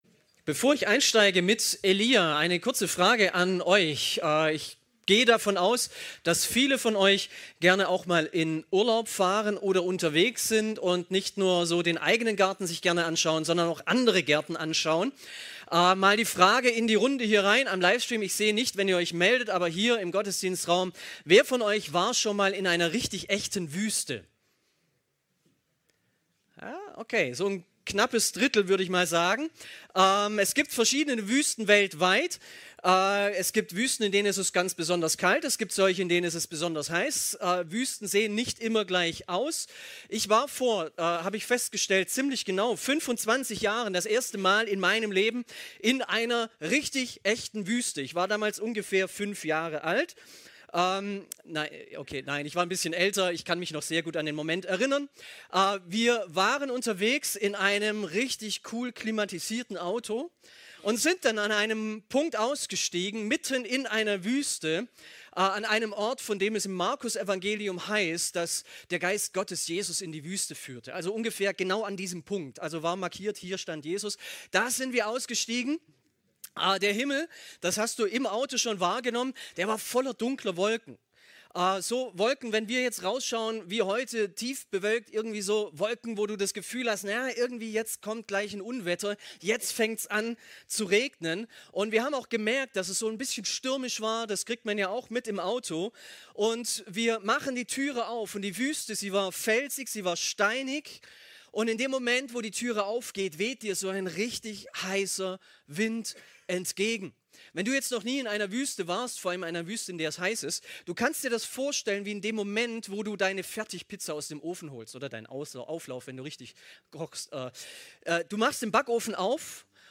Predigten | Panorama Kirche Göppingen :: verändert | gemeinsam | für Andere